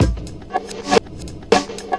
• 3. サンプル逆再生[B0, B1]・・・
B0でサンプルを逆再生、B1で再生を正方向に戻します。
lesson6-beat-03.mp3